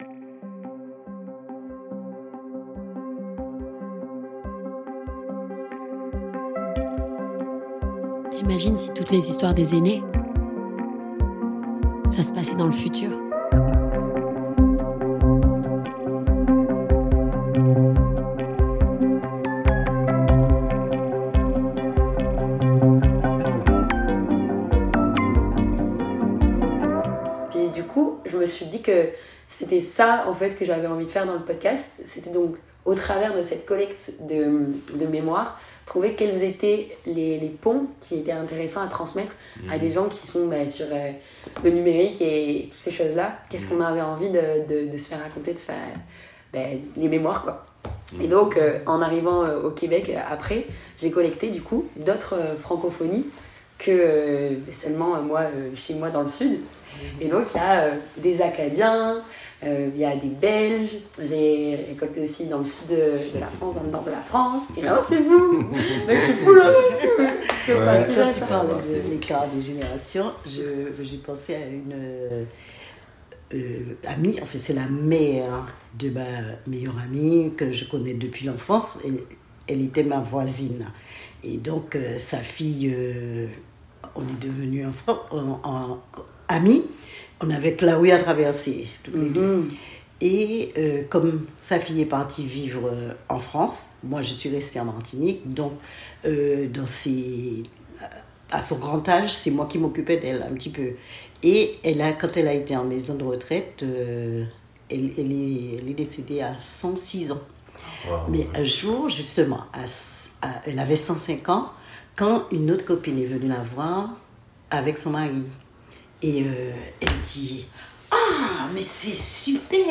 Vous entendez le vent qui souffle chaud, qui sent les épices et le rhum...
Entretien